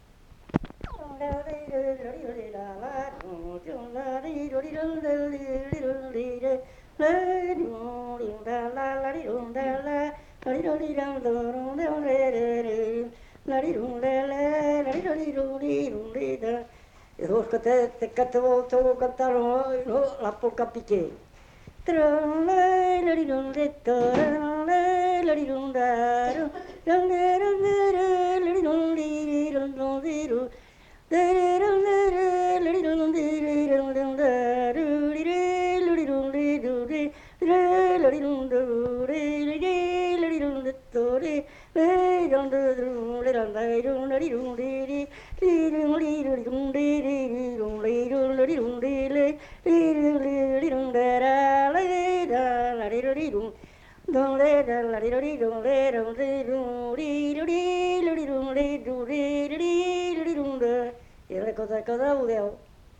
Genre : expression vocale
Effectif : 1
Type de voix : voix d'homme
Danse : polka piquée